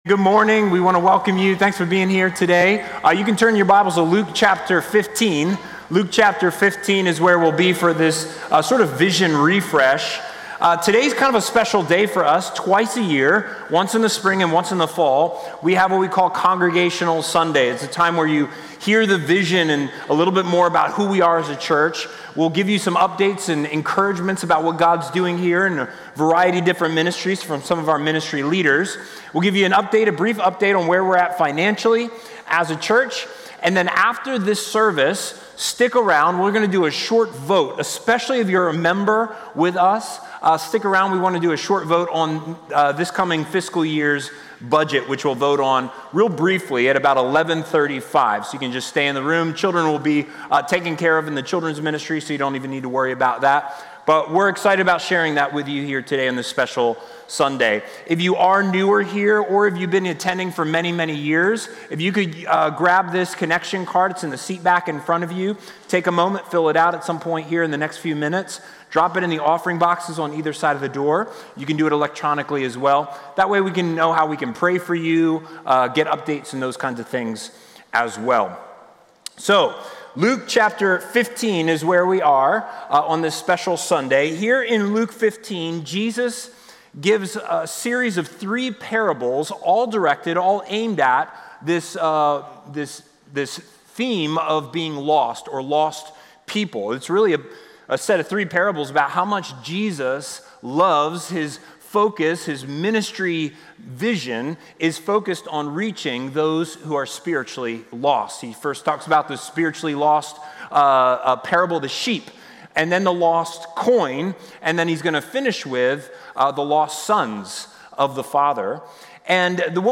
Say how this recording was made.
In addition, this Congregational Sunday we celebrate lives changed by Christ through baptisms and hear from our ministry leaders about what the Spirit is doing in our ministries.